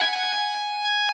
guitar_007.ogg